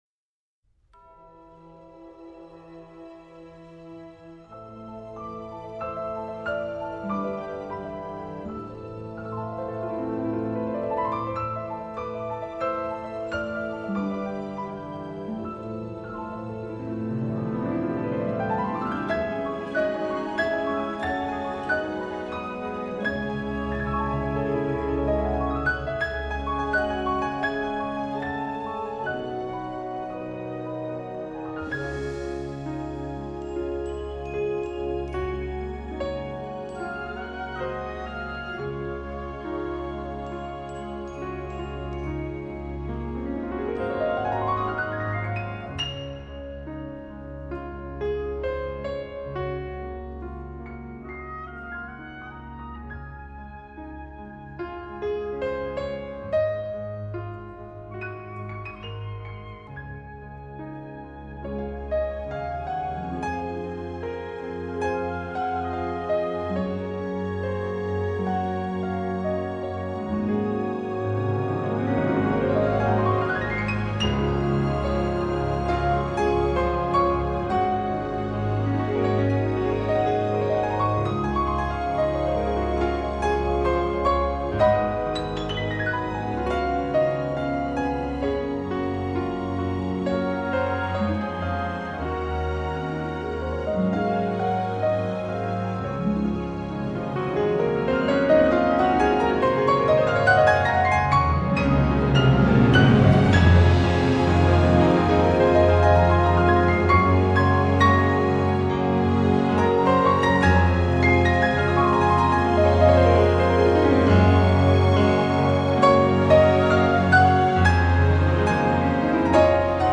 Classical, Crossover